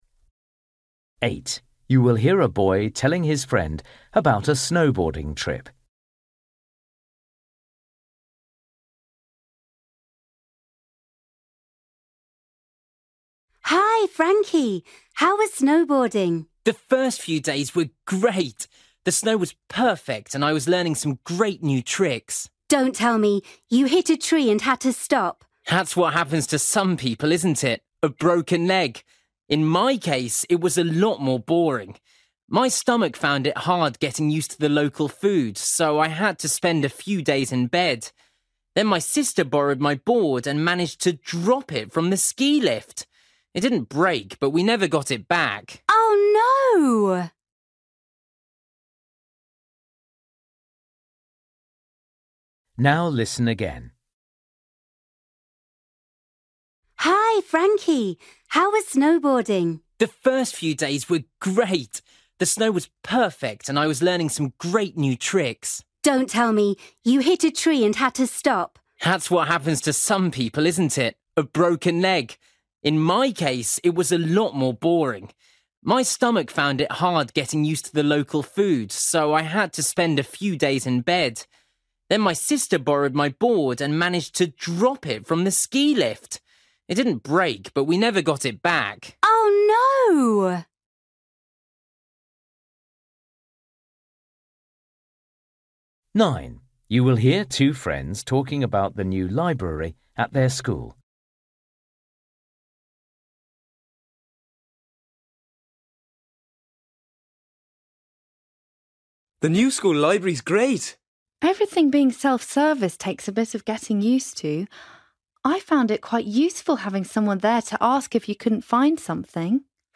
Listening: everyday short conversations
8   You will hear a boy telling his friend about a snowboarding trip.
9   You will hear two friends talking about the new library at their school.
10   You will hear two friends talking about a new clothes shop.